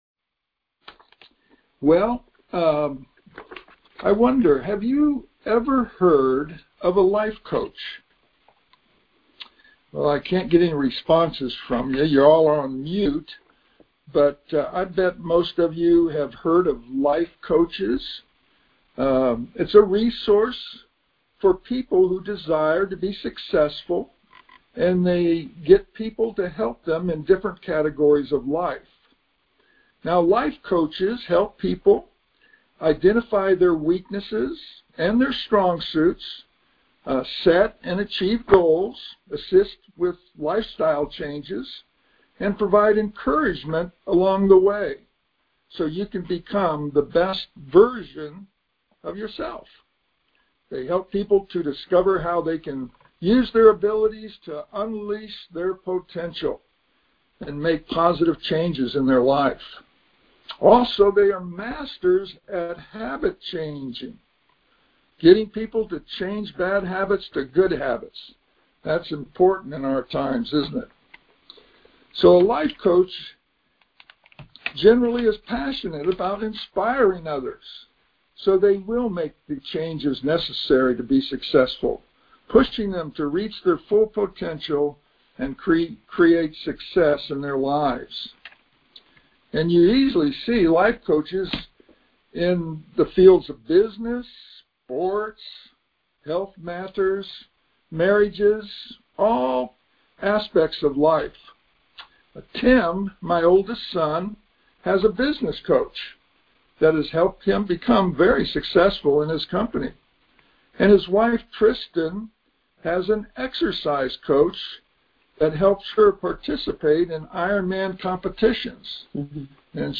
A life coach provides encouragement to become the best version of a person. We are now the sons of God (1 John 3:1) and this is the first part of a three part teaching on who we are in Christ.